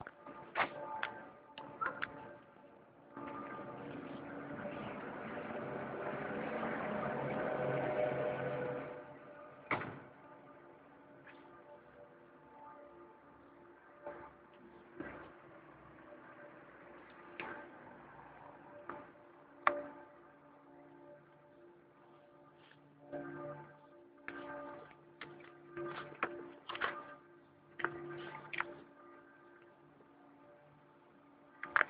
Bruits de feuilles, personnes qui parlent